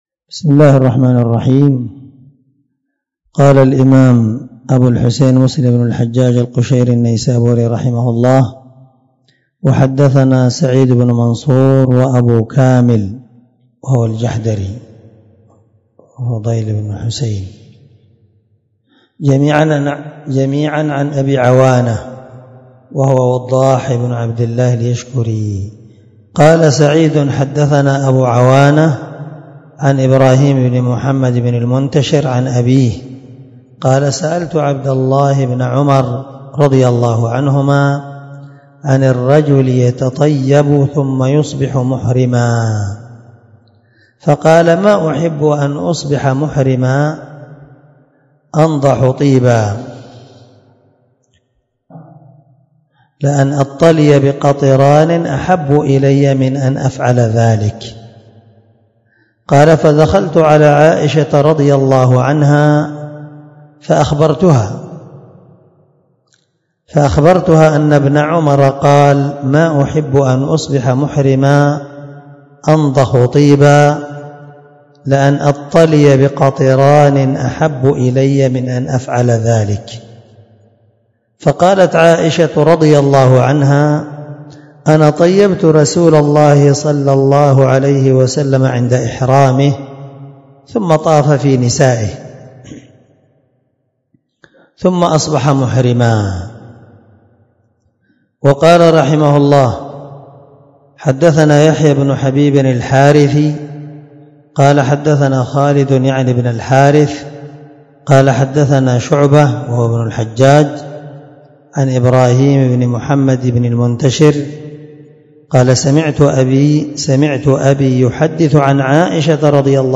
الدرس 7من شرح كتاب الحج حديث رقم(1192) من صحيح مسلم